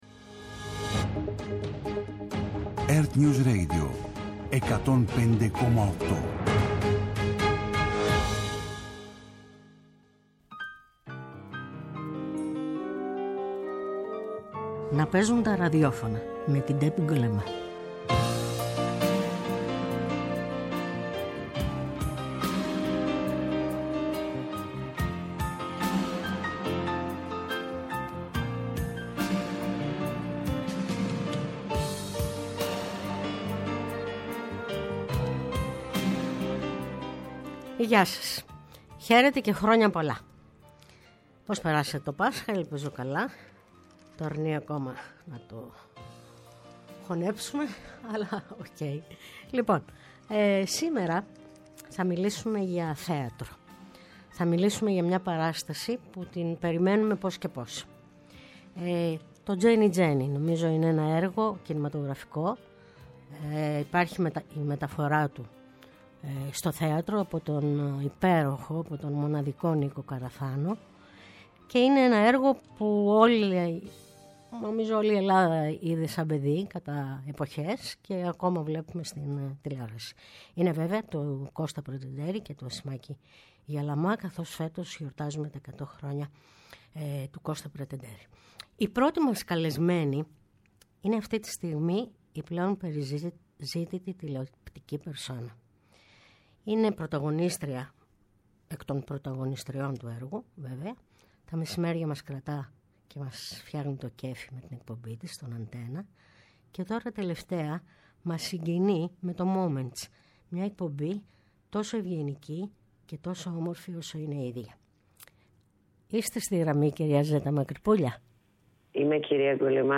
φιλοξενεί στο στούντιο ανθρώπους της Τέχνης -και όχι μόνο- σε ενδιαφέρουσες συζητήσεις με εξομολογητική και χαλαρή διάθεση.